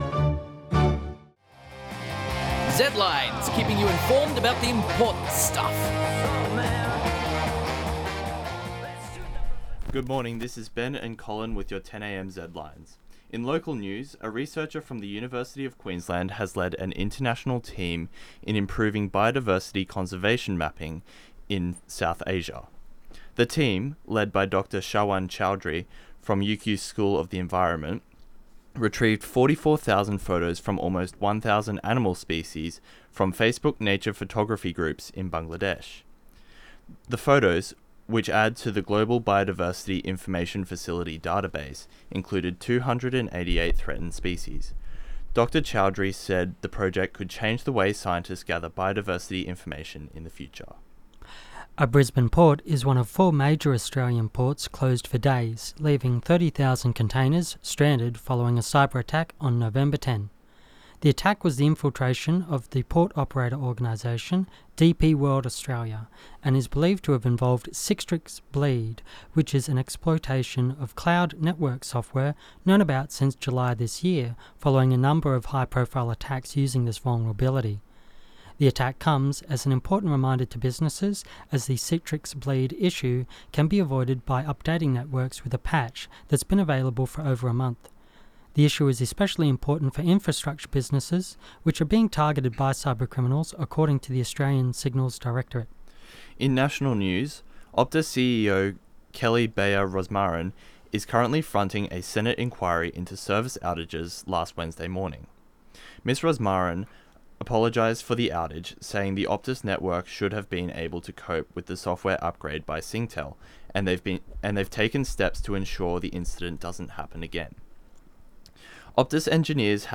Zedlines Bulletin Zedlines 2023-11-17 10am.mp3